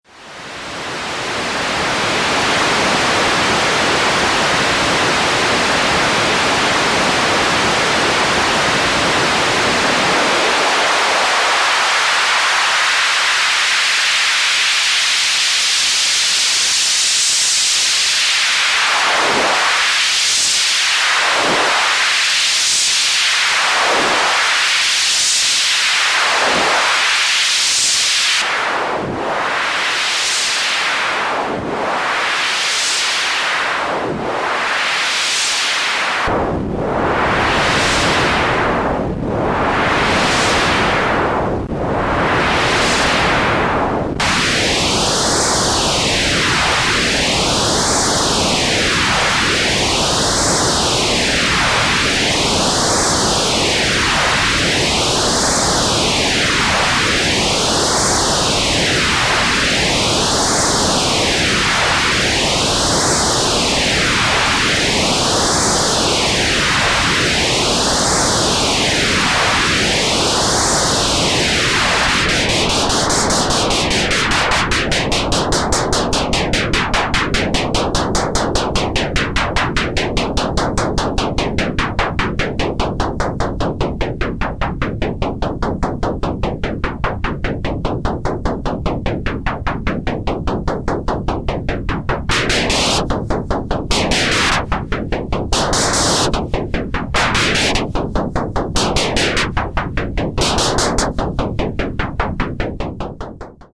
This is used to dynamically sweep the digital noise generator spectrum in the Monoladder.
software filter on noise, sound example from the MonoLadder
First you hear the noise, after 10 seconds the softwarefilter is on and LFO modulated, then switched from BandPass, HighPass to LowPass. The last filter setting (45sec up) is the Band Reject with a low Q, this sounds like a phasing effect. At 1m:12s the hardware analog lowpassfilter is swept down with a sawtooth LFO modulation (while pushing the MonoLadder softpot slider a few times).
noisefilter-demo1.mp3